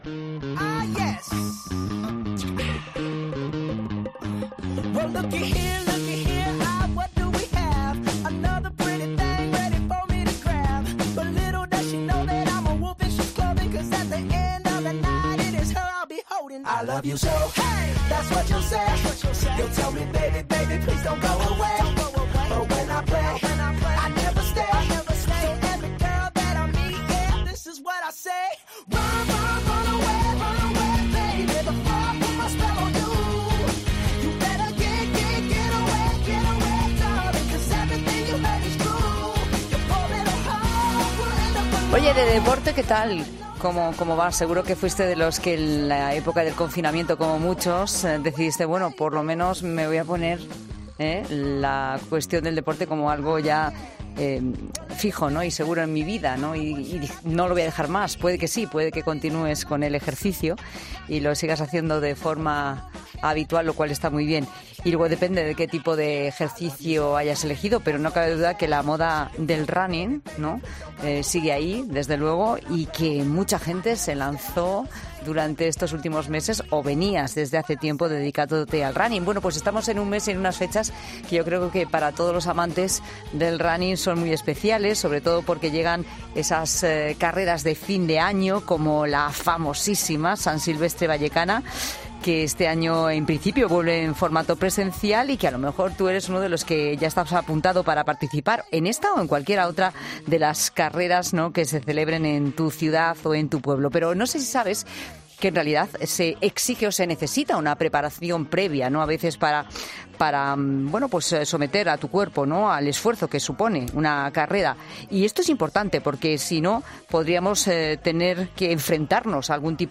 Hablamos con la doctora